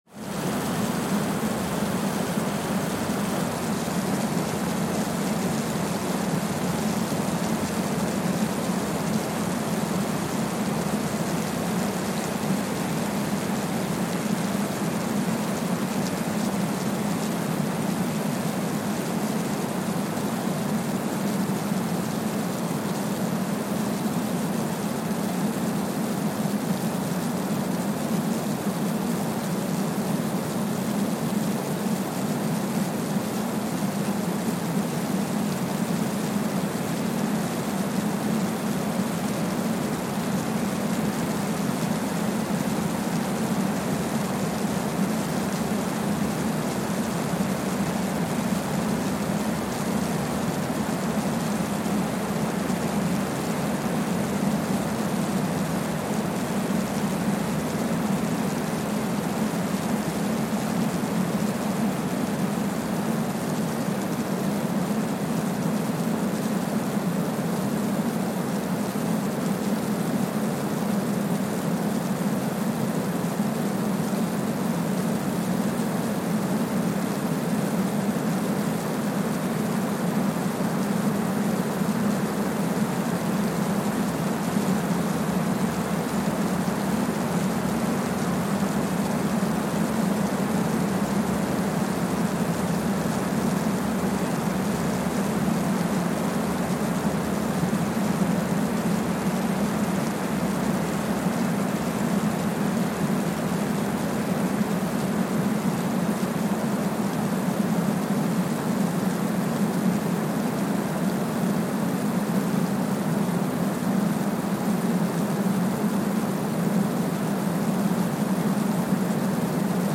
Kwajalein Atoll, Marshall Islands (seismic) archived on May 18, 2023
Sensor : Streckeisen STS-5A Seismometer
Speedup : ×1,000 (transposed up about 10 octaves)
Loop duration (audio) : 05:45 (stereo)
Gain correction : 25dB